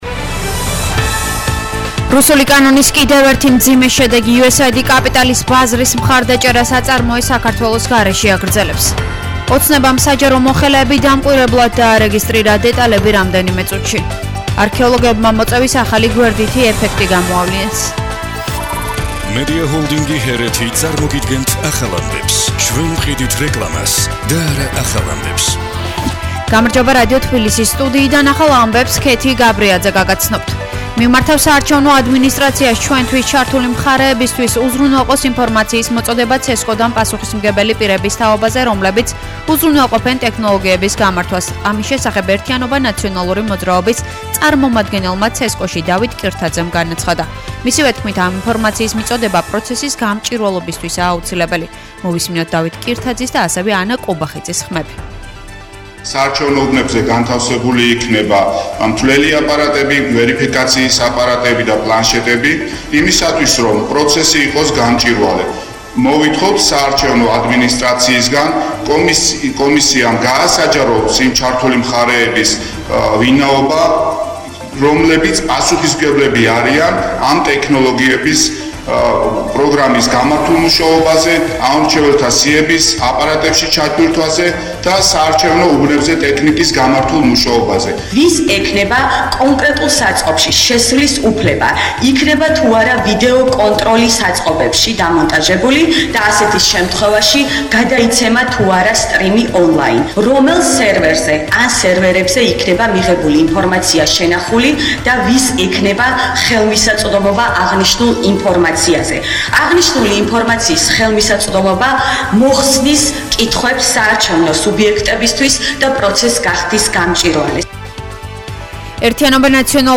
ახალი ამბები 19:00 საათზე